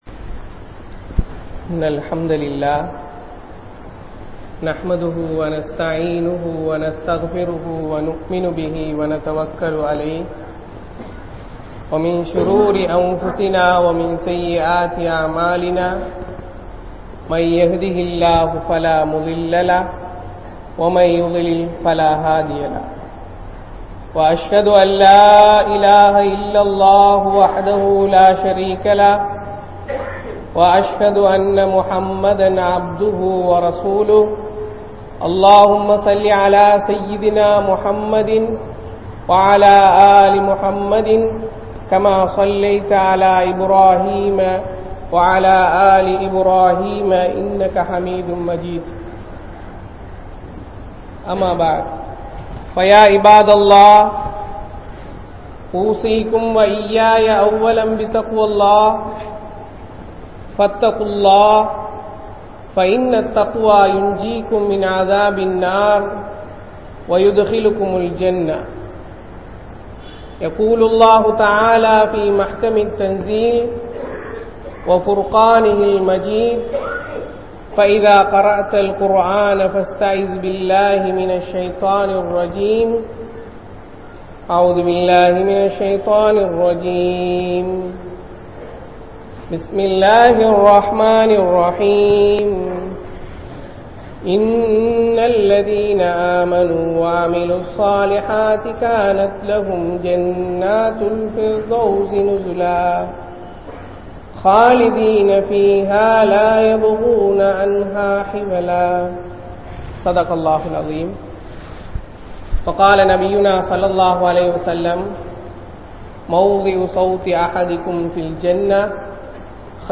Manithan Padaikkap Pattathan Noakkam (மனிதன் படைக்கப்பட்டதன் நோக்கம்) | Audio Bayans | All Ceylon Muslim Youth Community | Addalaichenai
Grand Jumua Masjith